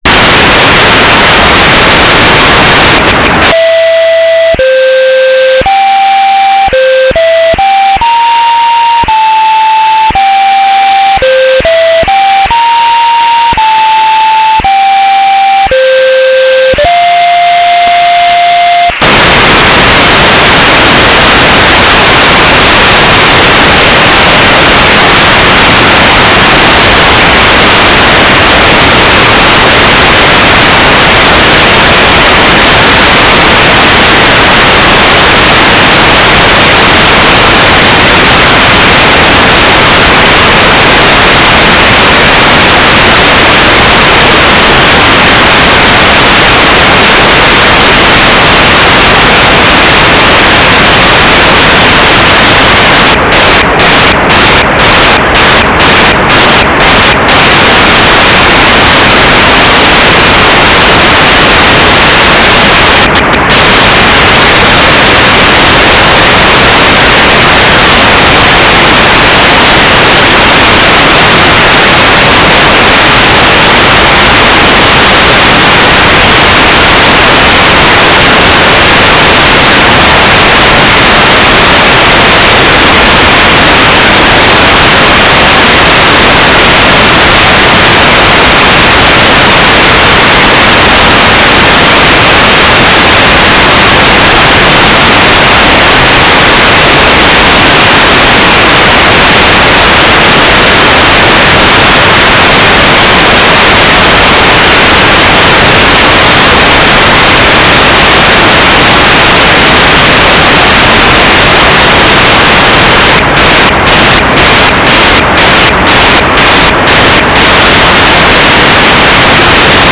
"transmitter_baud": 4800.0,